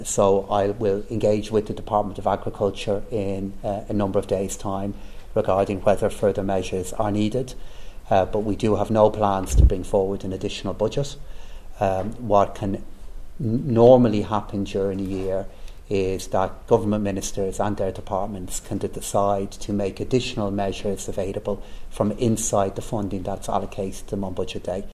However, Public Expenditure Minister Pascal Donohoe says he has no plans to make addition funding available to farmers.